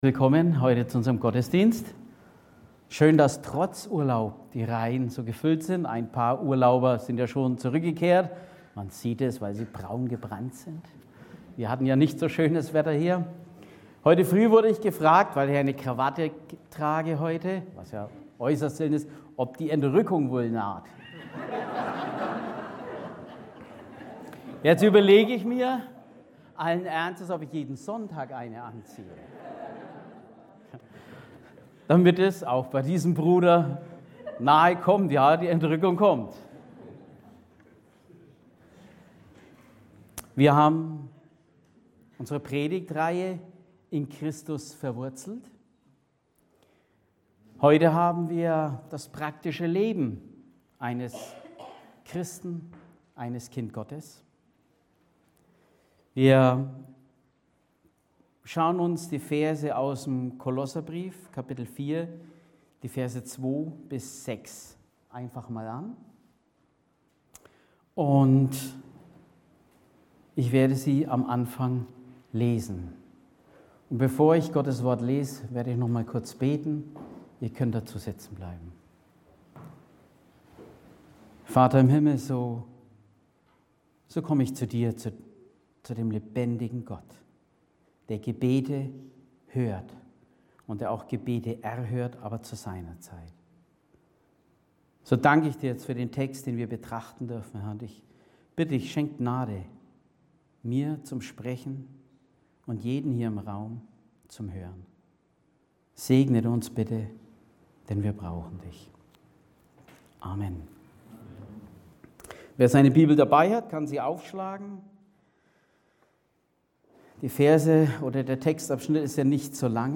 predigte